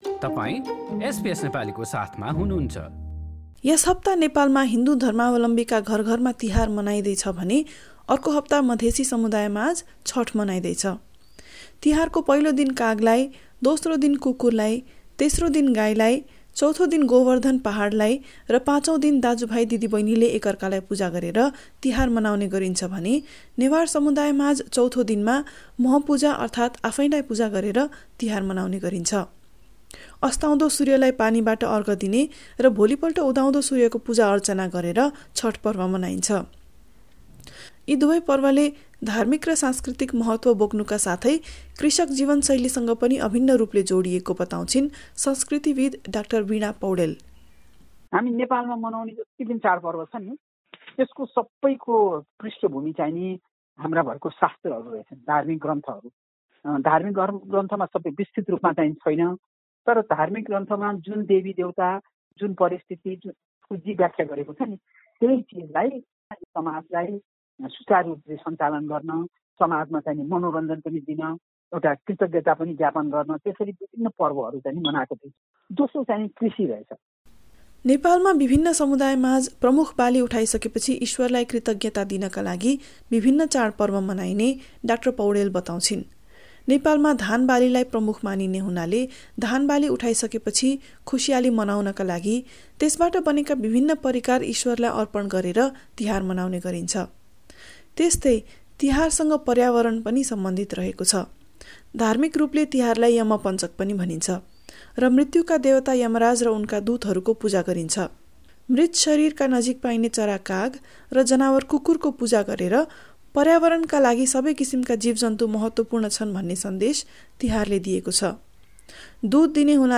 यस हप्ता हिन्दु धर्मावलम्बीका घरघरमा तिहार मनाइँदै छ भने अर्को हप्ता मधेसी समुदायमाझ छठ मनाइँदै छ। काठमाण्डूबाट
रिपोर्ट